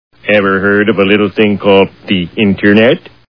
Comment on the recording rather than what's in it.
The Simpsons [Homer] Cartoon TV Show Sound Bites